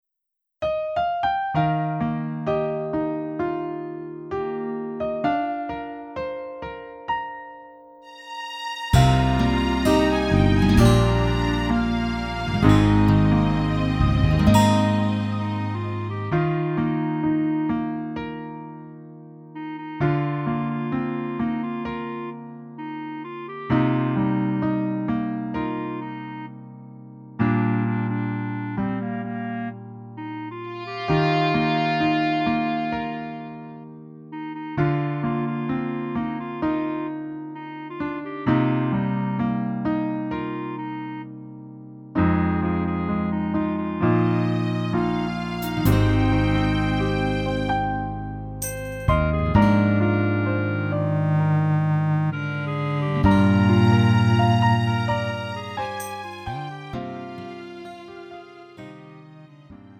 음정 -1키 4:17
장르 가요 구분 Lite MR
Lite MR은 저렴한 가격에 간단한 연습이나 취미용으로 활용할 수 있는 가벼운 반주입니다.